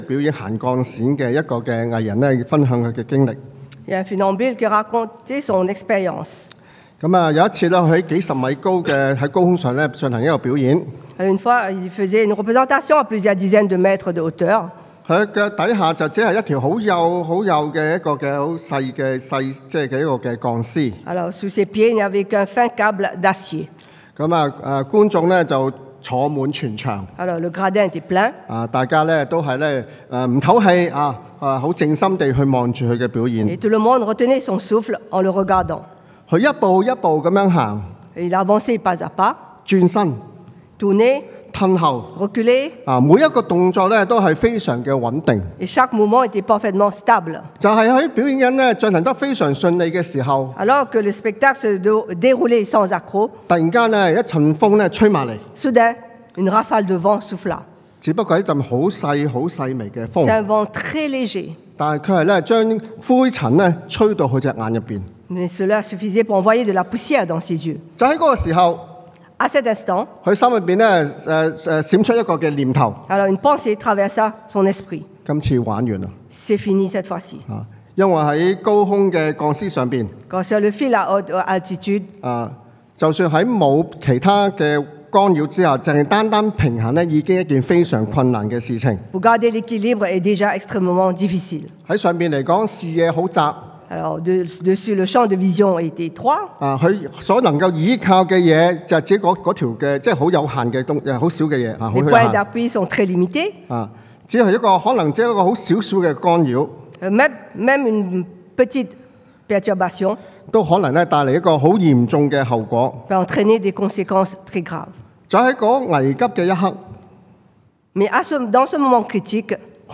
Enlever d’abord ses sandales avant de partir au combat 先脱鞋，再出征 – Culte du dimanche